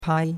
pai1.mp3